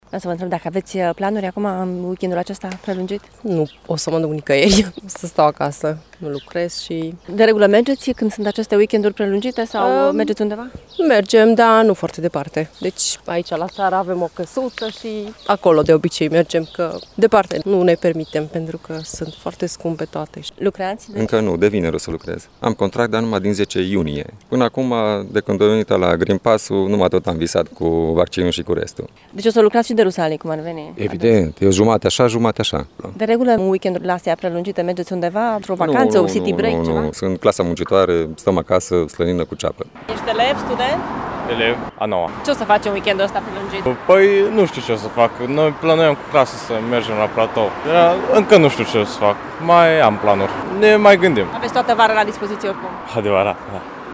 În schimb, alți târgumureșeni rămân acasă în minivacanța de Rusalii. Oamenii spun că prețurile sunt atât de mari încât nu oricine își mai permite vacanțe: